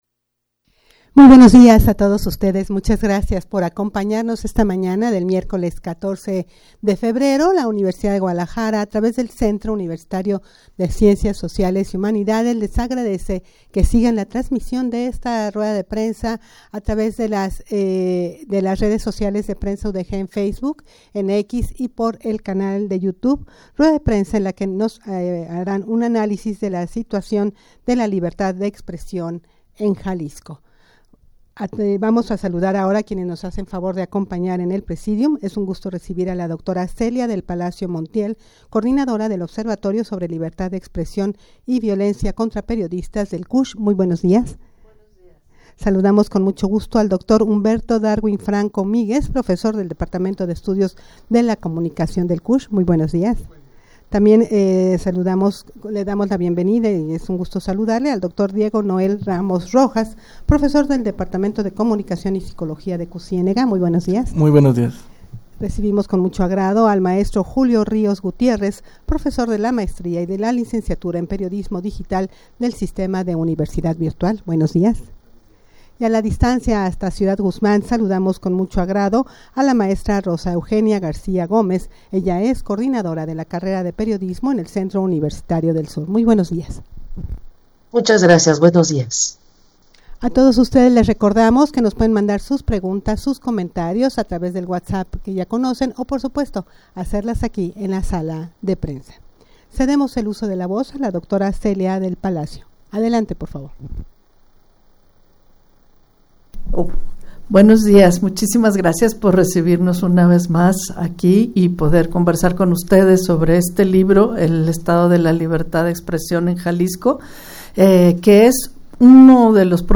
rueda-de-prensa-para-analizar-la-situacion-de-la-situacion-de-la-libertad-de-expresion-en-jalisco.mp3